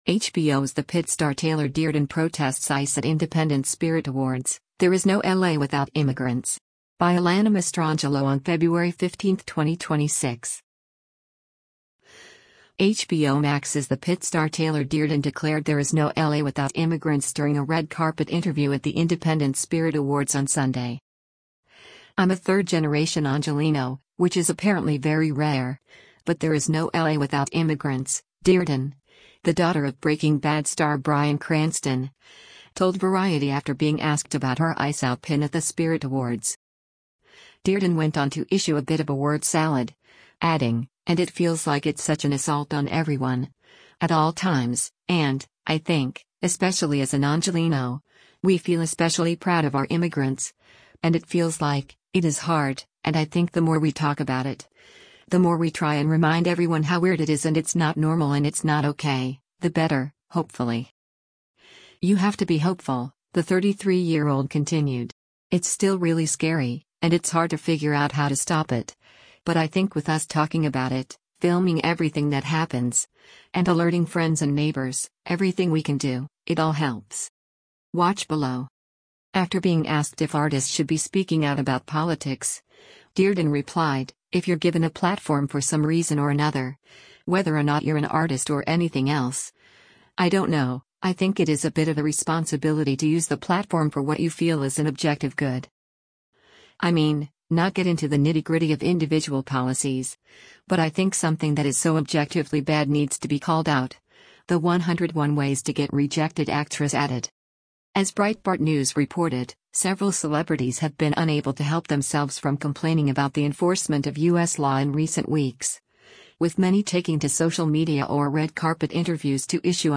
HBO Max’s The Pitt star Taylor Dearden declared “there is no L.A. without immigrants” during a red carpet interview at the Independent Spirit Awards on Sunday.